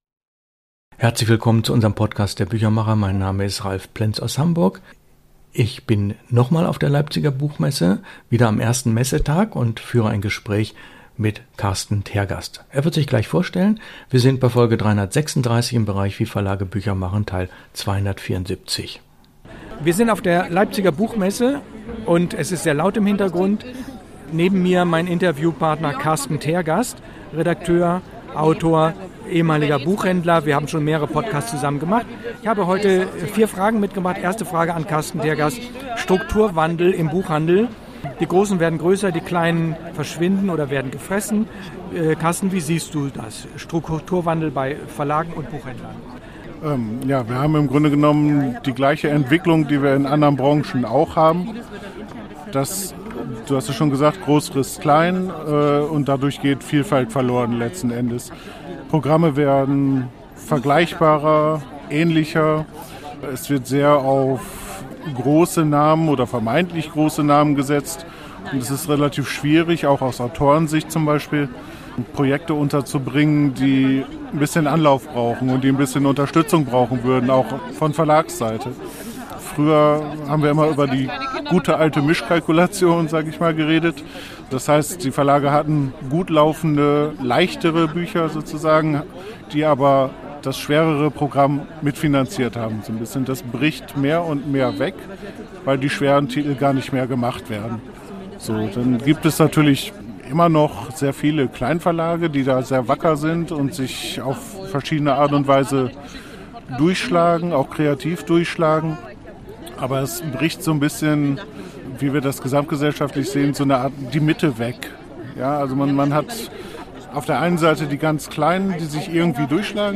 Interview mit dem Buchhändler